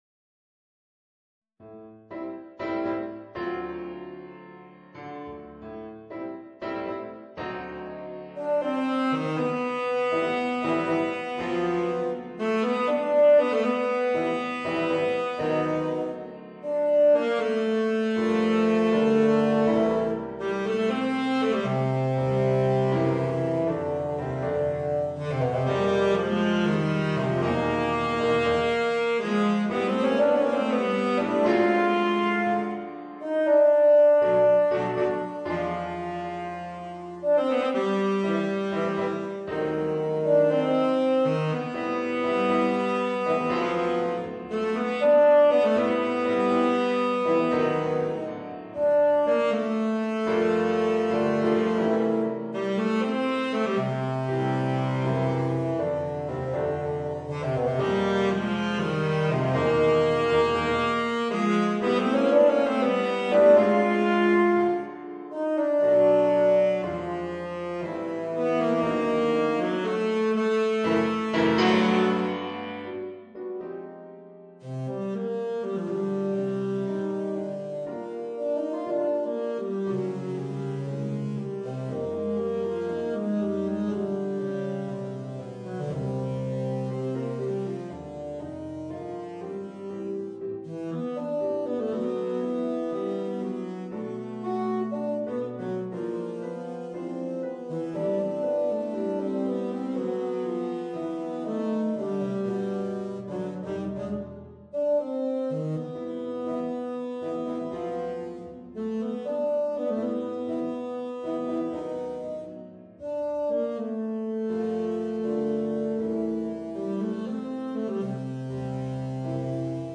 2 Tenor Saxophones and Piano